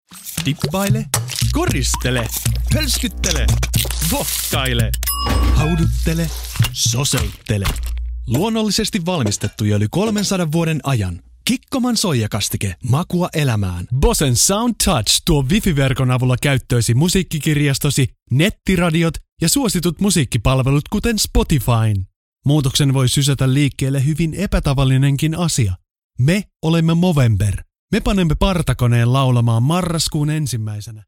Finnish, Scandinavian, Male, 20s-30s